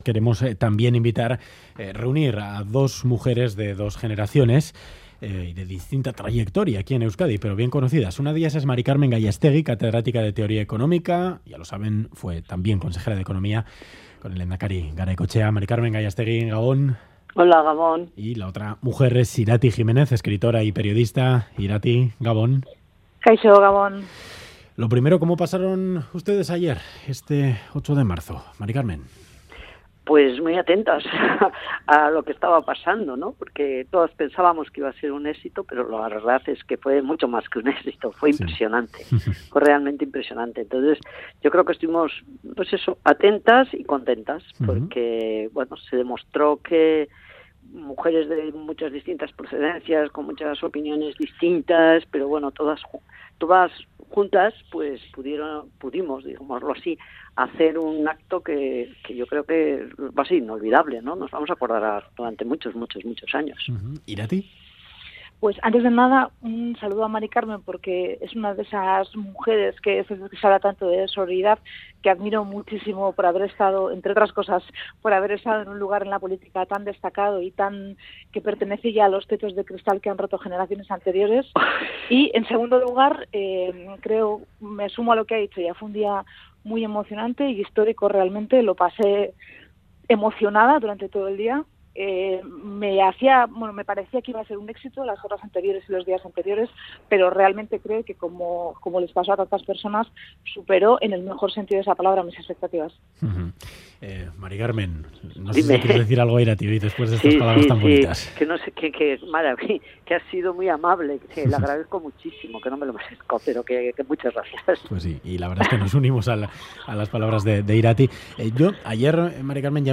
Entrevista en Ganbara a la Catedrática de Teoría Económica y ex consejera de Económica